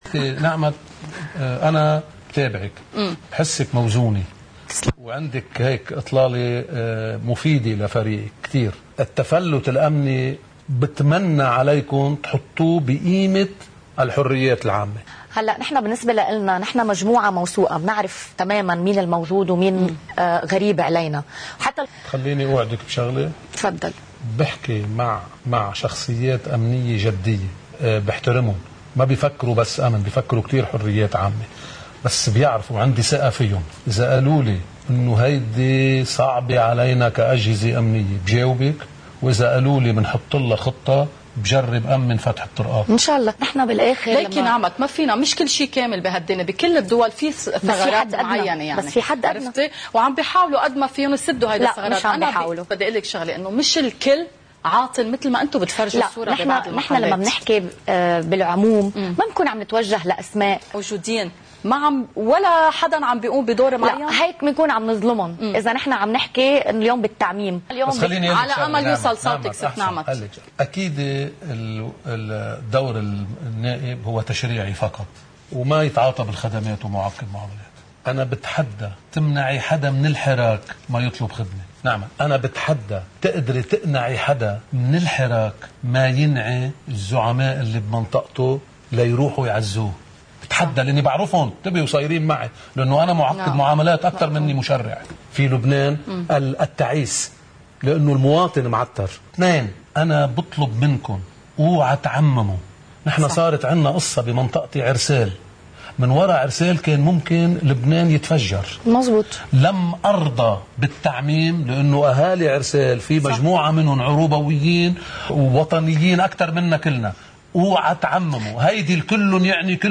مقتطف من حديث النائب اميل رحمة ضمن برنامج “حكي على المكشوف” على قناة الـ”OTV”: